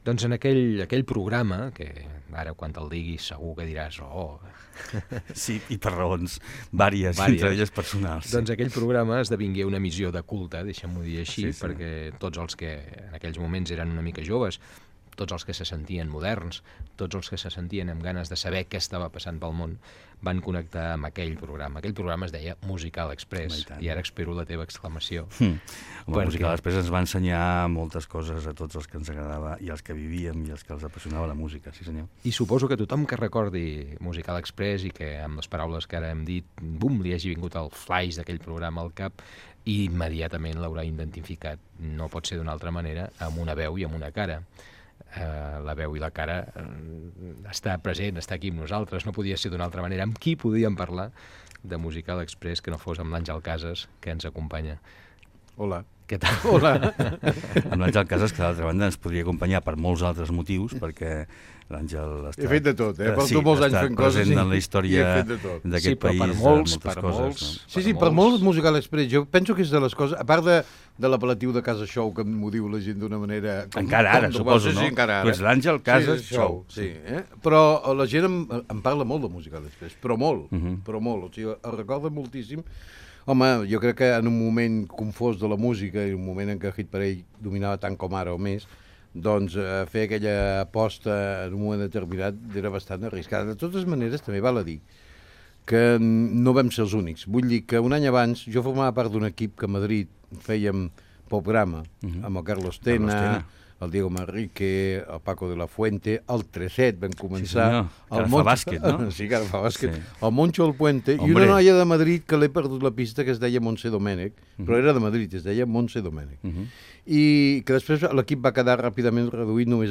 Gènere radiofònic Entreteniment
Fragment extret de l'arxiu sonor de COM Ràdio.